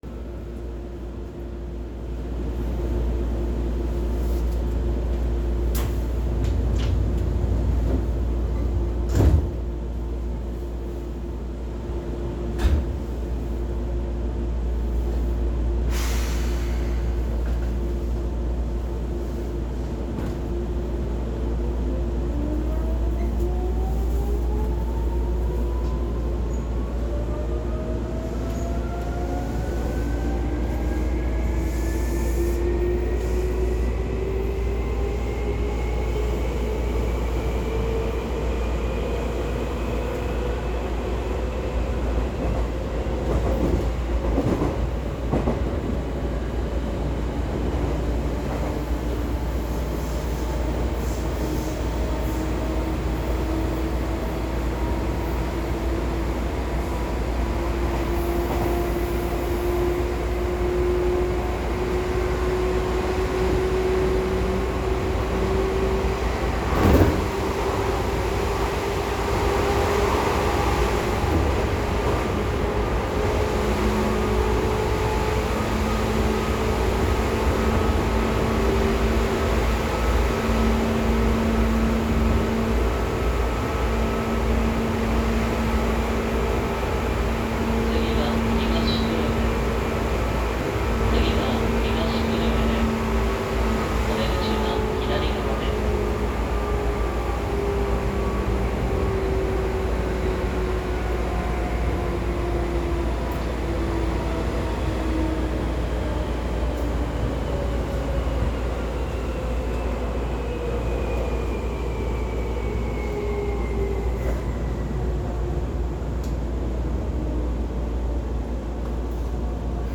・新2000系走行音
【池袋線】ひばりが丘→東久留米
…まぁ一応新2000系としましたが、音に関しては2000系も新2000系も一切変わりません。何と東急の8500系と全く同じ音がします。ブレーキ解除音とドアの音以外は何も変わらないと言っても過言ではないほどです。
Hibari-Kurume.mp3